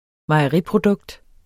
Udtale [ mɑjʌˈʁi- ]